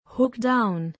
hook-down.mp3